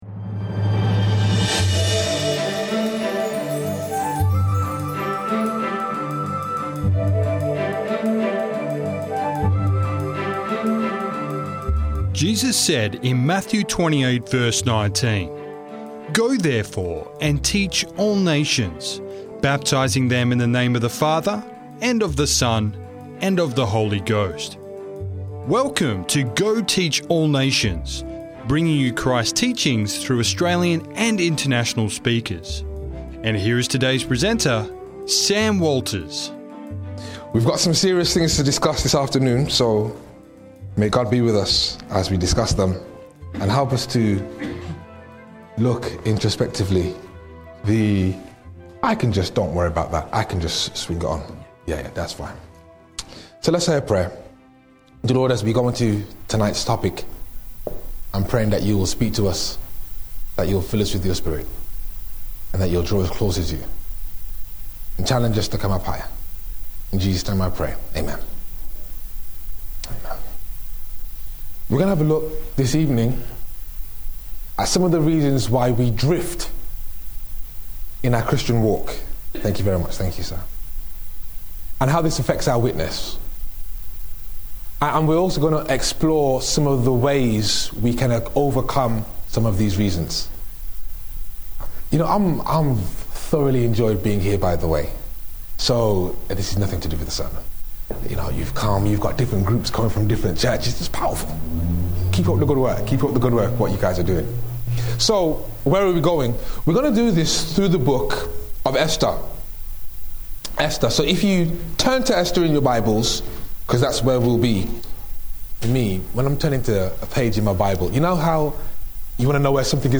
Have you compromised so much that you feel trapped in your own decisions? This powerful sermon explores the Book of Esther to reveal why Christians drift in their spiritual walk and how to overcome it.